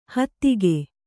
♪ hattige